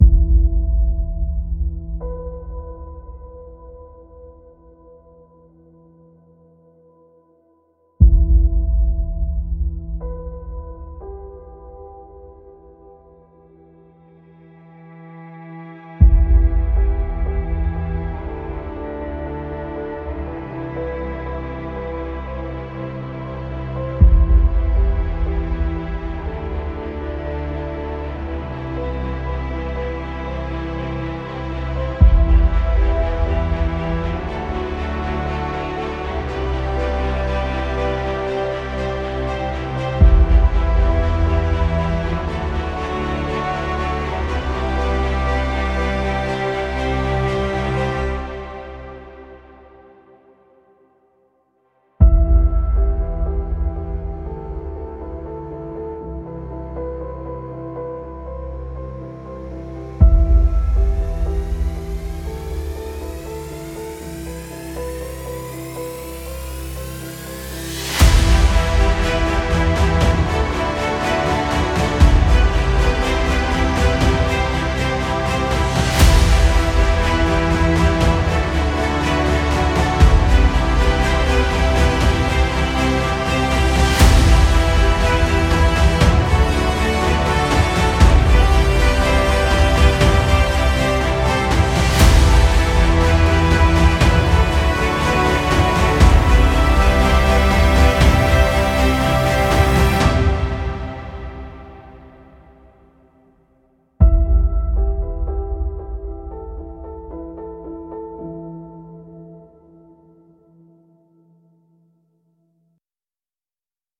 Genre: filmscore.